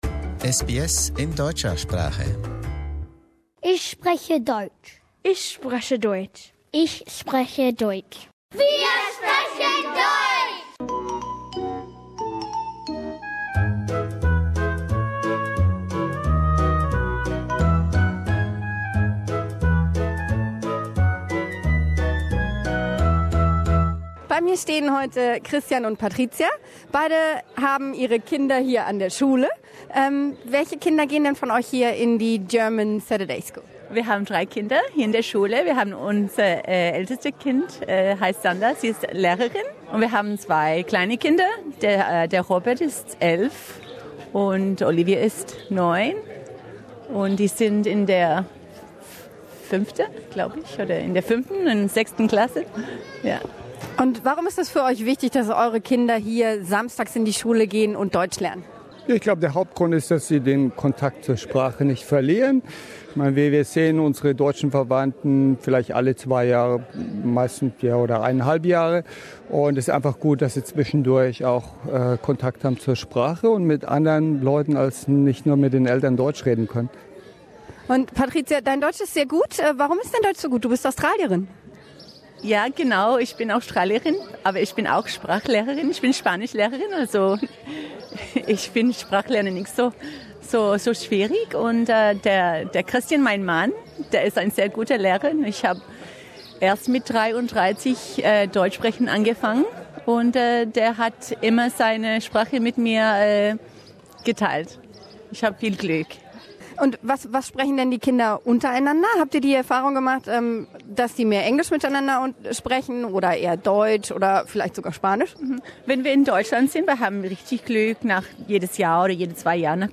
Die vielen neuen Gesichter an der deutschen Samstagsschule blicken auf eine alte Tradition zurück. Wir haben sie mit dem Mikro zum Tag der offenen Tür besucht.